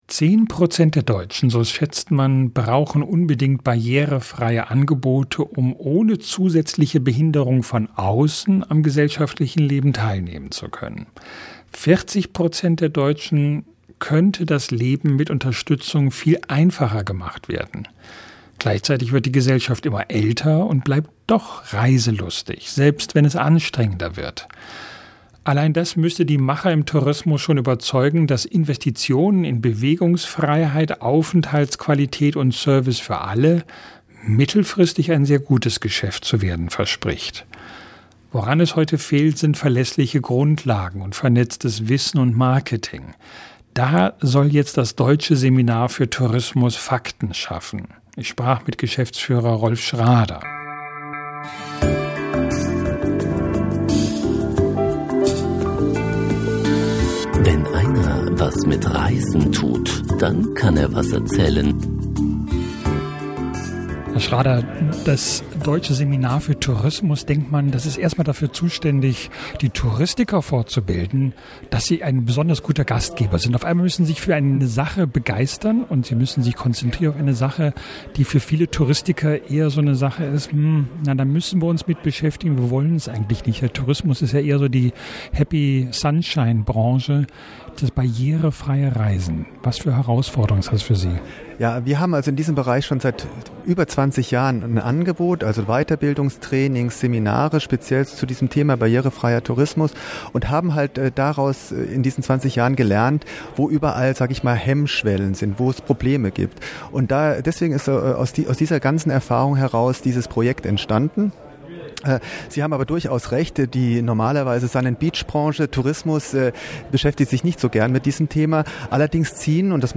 Reiseradio